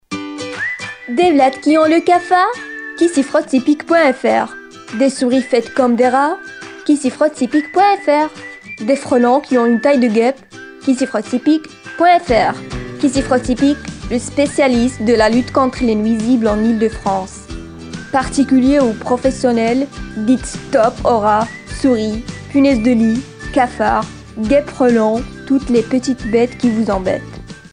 女法102 法语女声 低沉|激情激昂|大气浑厚磁性|沉稳|娓娓道来|科技感|积极向上|时尚活力|神秘性感|调性走心|亲切甜美|感人煽情|素人